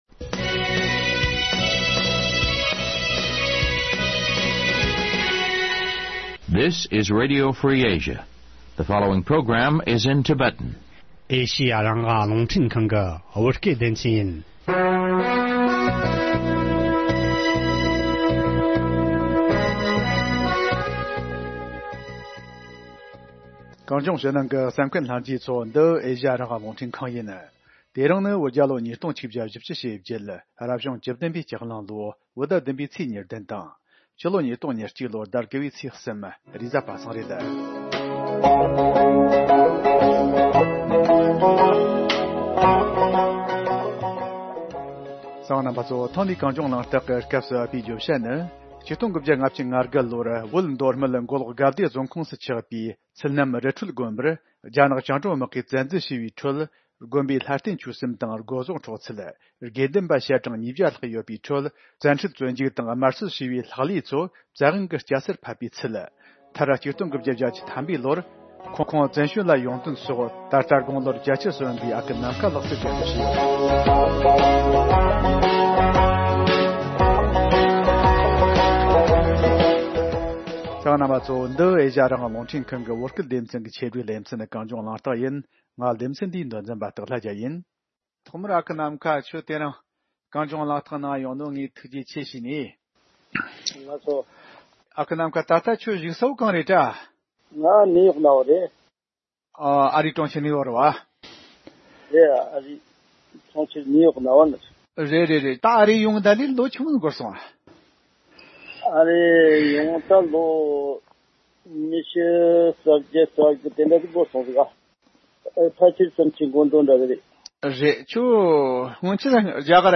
བཅར་འདྲི་བྱས་པའི་ལས་རིམ།